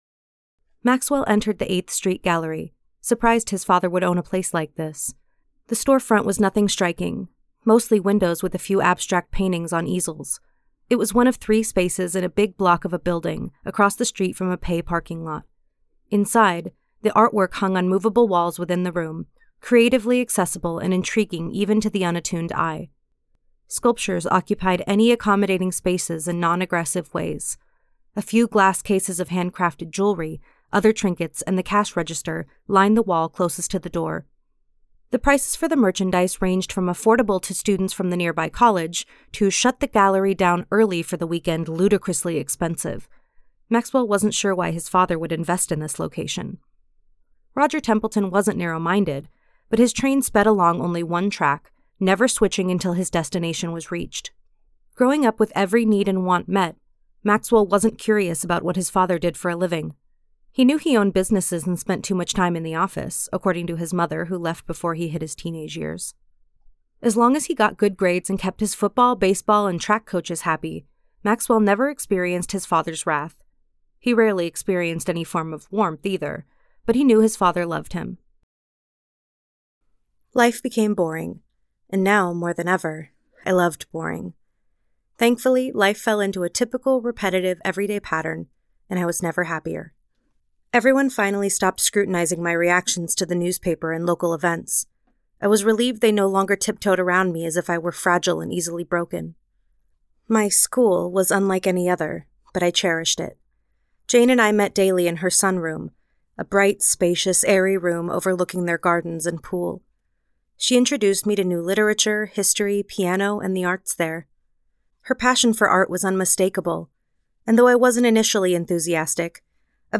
Audio Book Voice Over Narrators
Adult (30-50) | Yng Adult (18-29)
Professional story tellers with years of experience in audio book narration are available to record your next project.